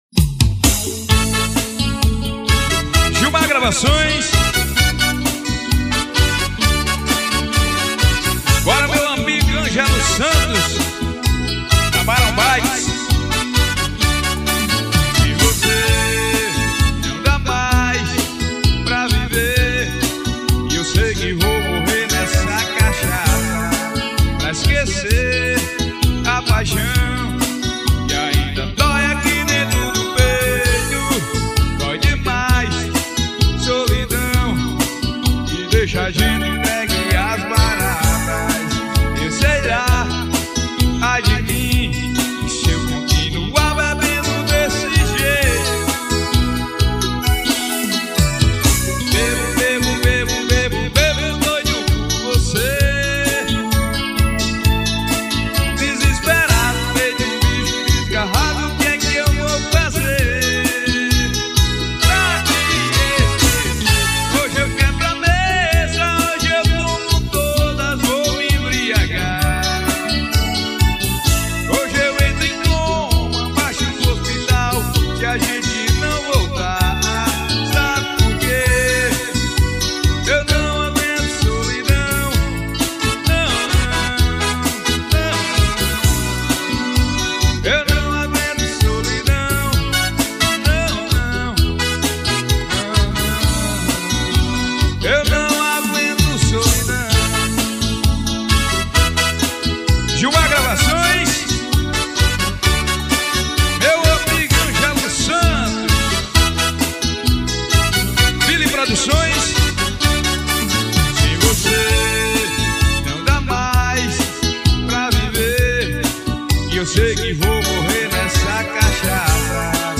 AO VIVO EM OROBÓ.